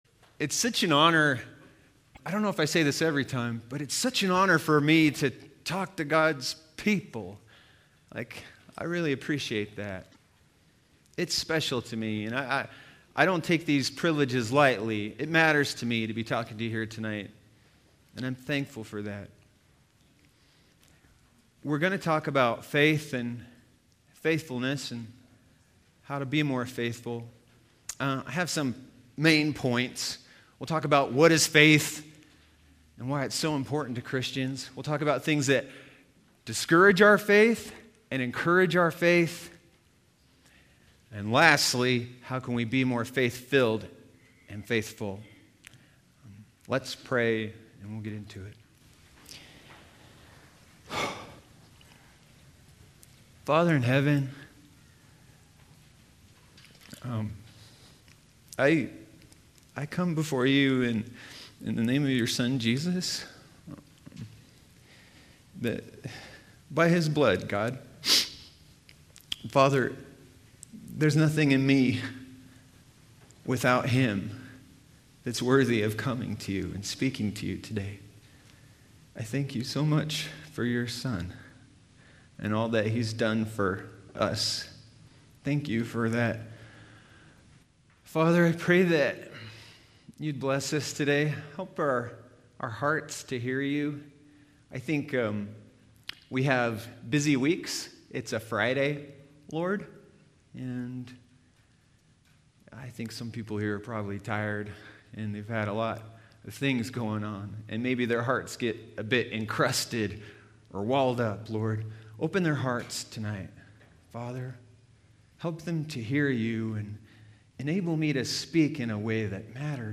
teaches on the importance of the Christian faith.